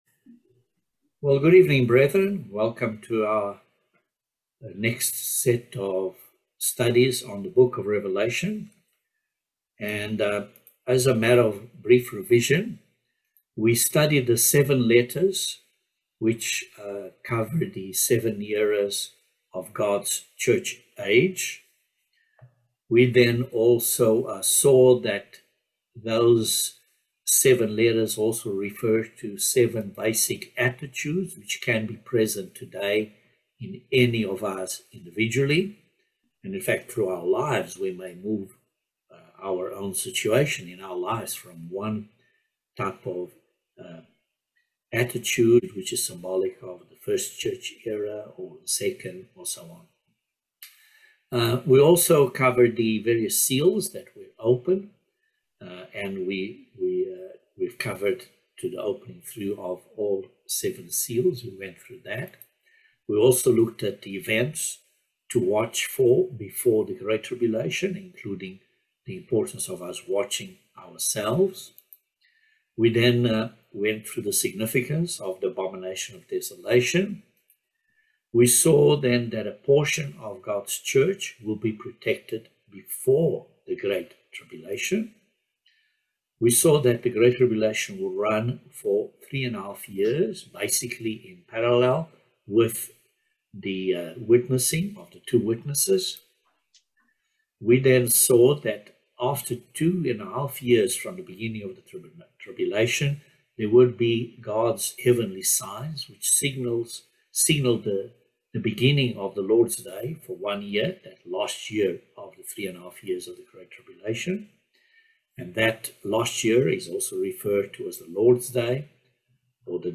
Bible Study No 21 of Revelation